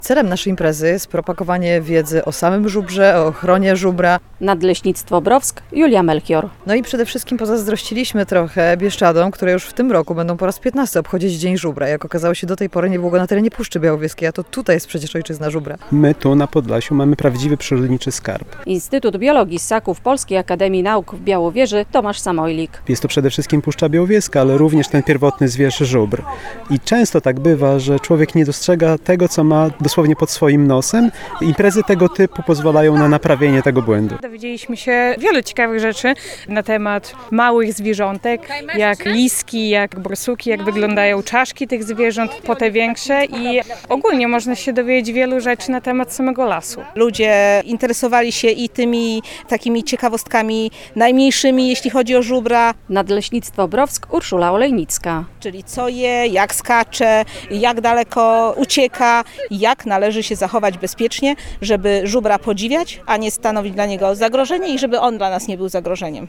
W Nadleśnictwie Browsk koło Narewki na północy Puszczy Białowieskiej odbył się piknik edukacyjny „Dzień Żubra w Gruszkach”.
relacja